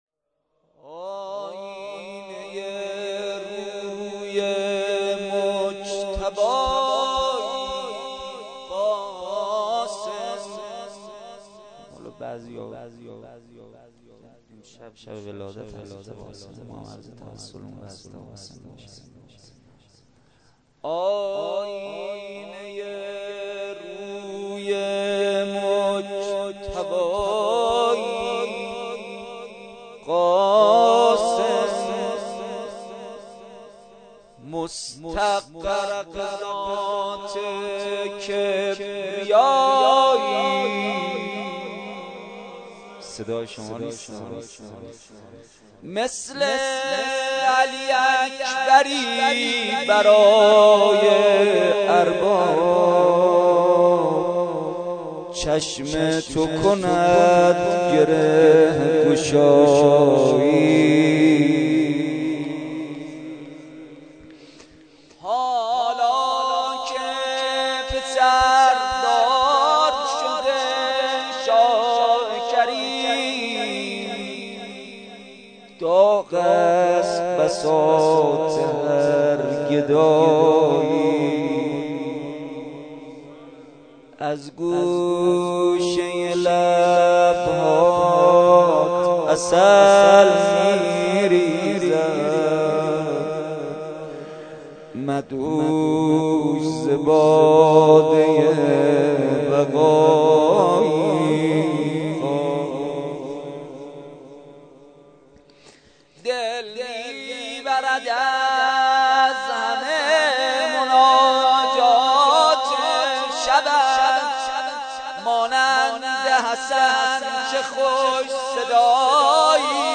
شب ششم ماه رمضان با مداحی کربلایی محمدحسین پویانفر در ولنجک – بلوار دانشجو – کهف الشهداء برگزار گردید.
کد خبر : ۵۶۵۷۲ عقیق:صوت این جلسه را بشنوید: دعا و مناجات روضه لینک کپی شد گزارش خطا پسندها 0 اشتراک گذاری فیسبوک سروش واتس‌اپ لینکدین توییتر تلگرام اشتراک گذاری فیسبوک سروش واتس‌اپ لینکدین توییتر تلگرام